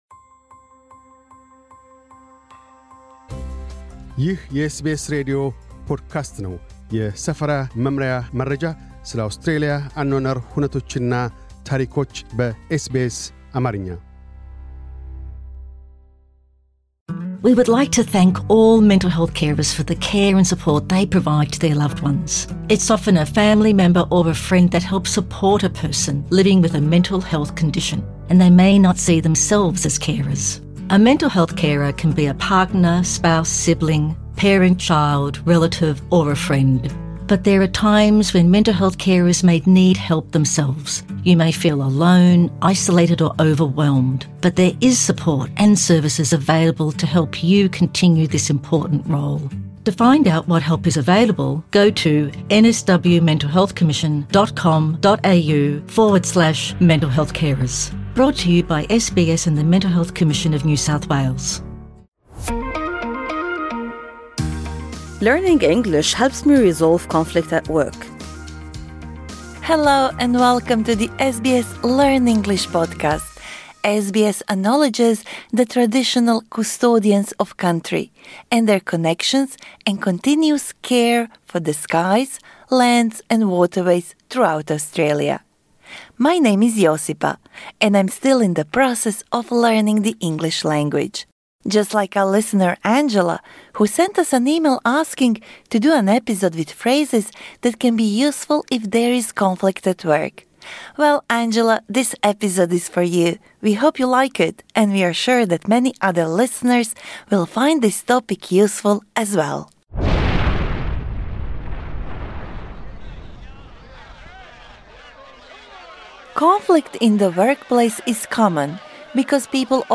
This lesson suits upper-intermediate to advanced learners.